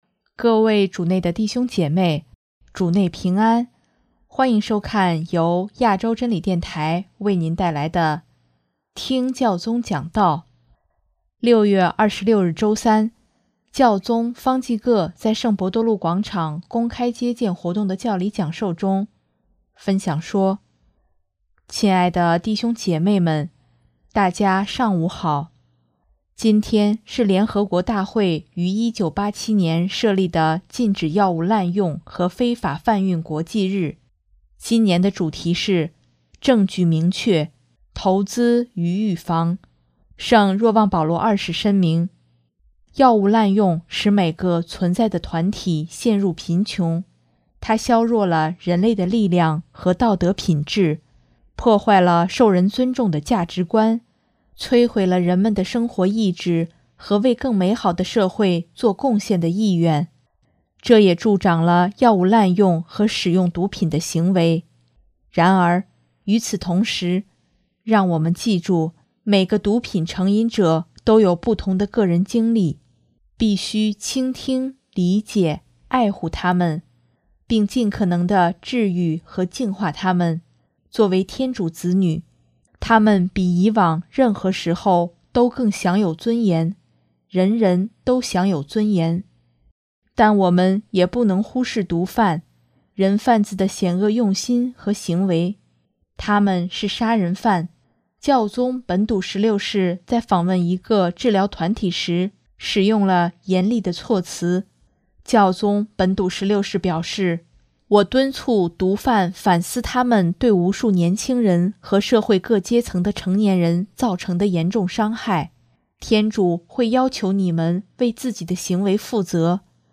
6月26日周三，教宗方济各在圣伯多禄广场公开接见活动的教理讲授中，分享说：